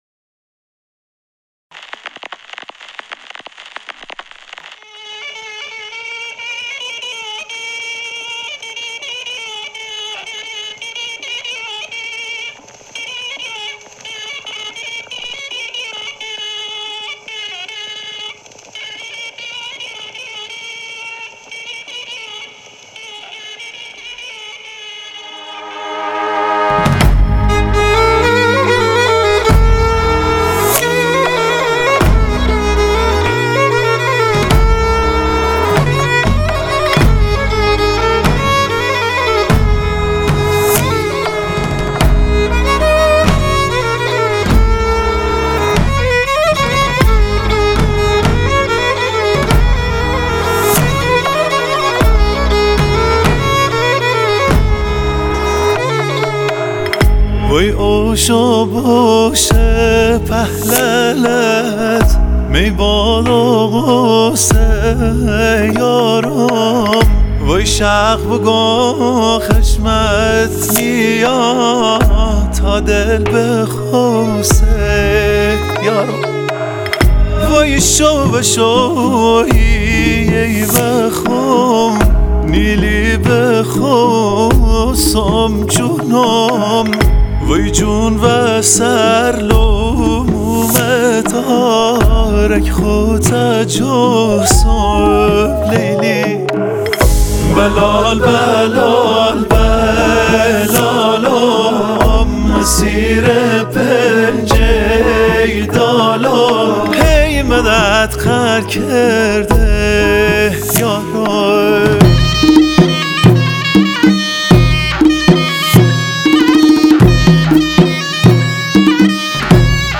ملودی : فولک
کرنا و ویالن